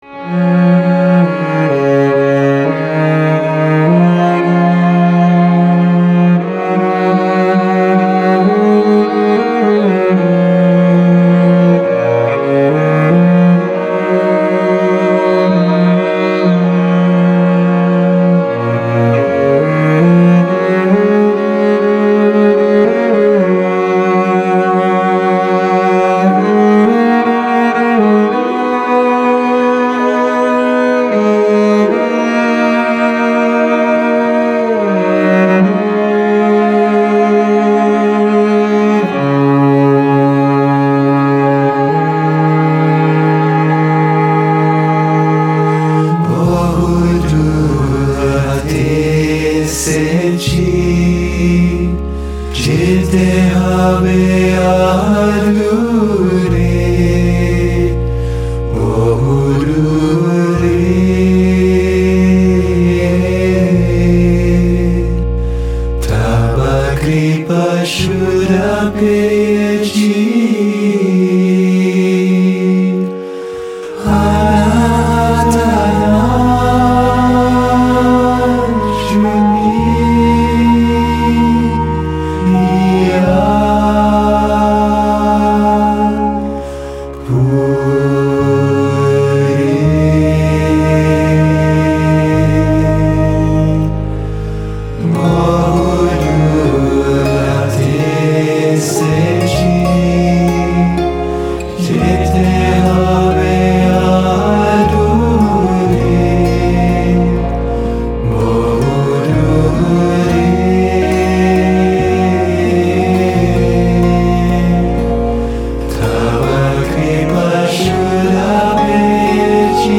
meditative music